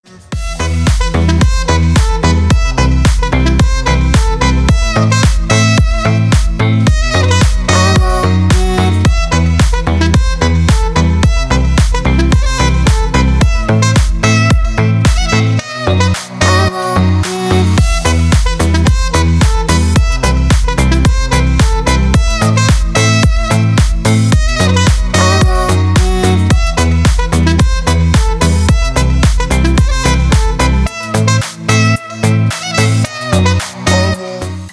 громкие
Electronic
EDM
Саксофон
Стиль: deep house